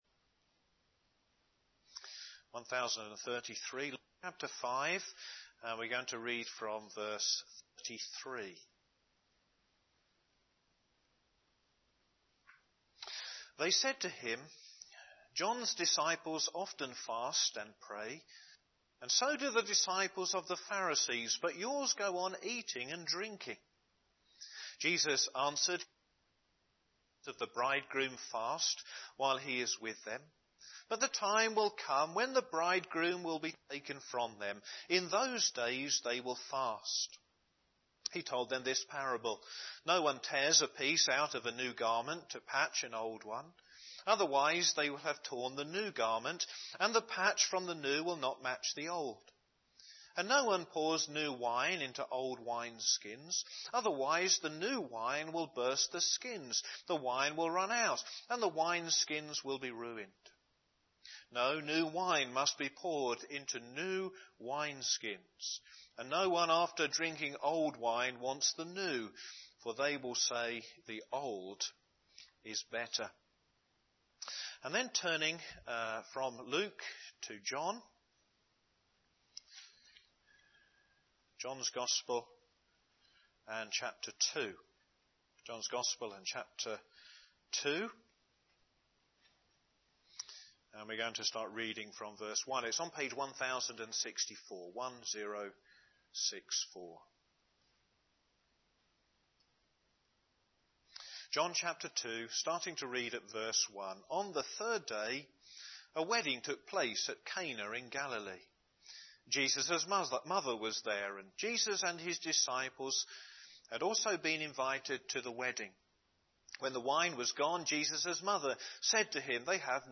Home Church Sermons Law and Grace